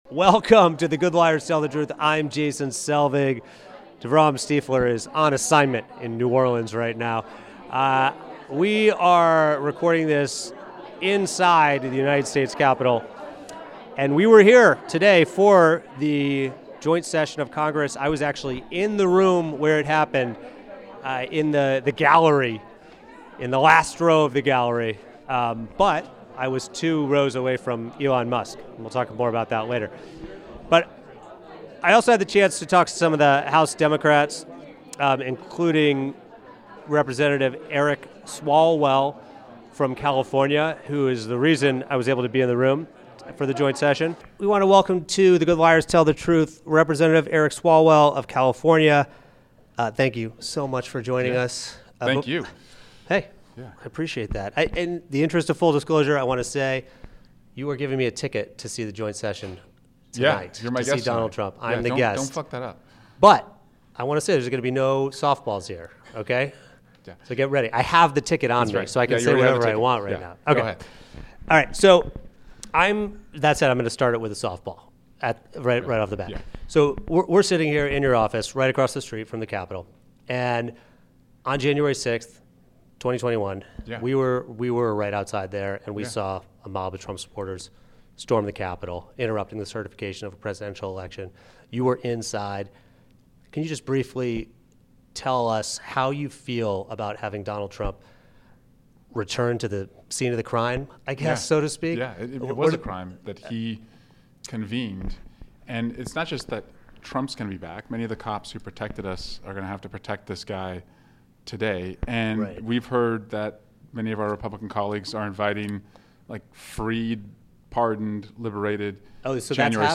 We were inside the capitol for Trump's address. We tried to find out what the democratic plan is moving forward and the results were... mixed. Featuring interviews with Representatives.